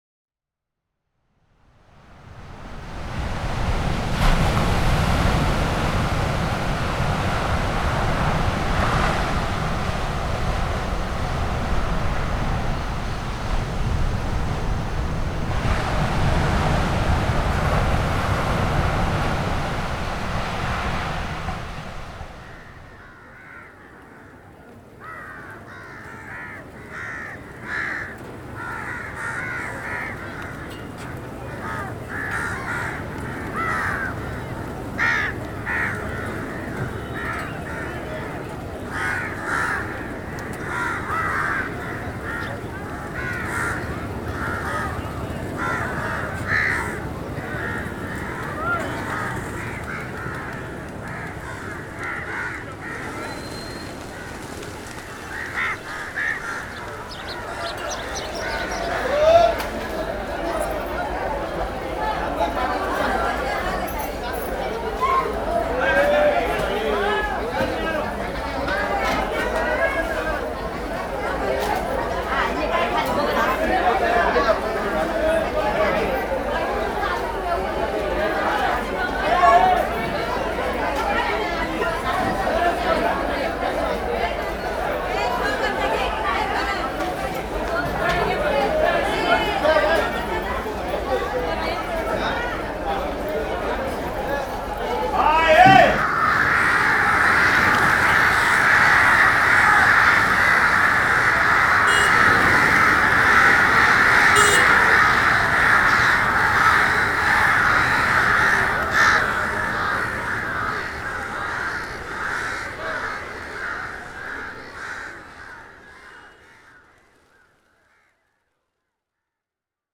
Field recording
Enregistrement d’ambiances sonores à la campagne ou en ville